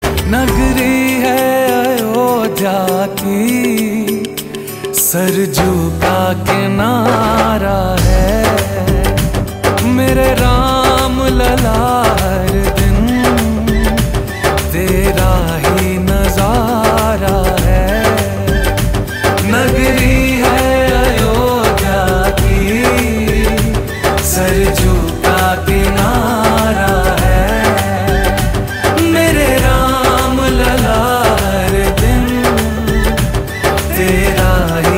Download devotional MP3 ringtone with loud, clear sound.
• Pure devotional (Bhakti) vibe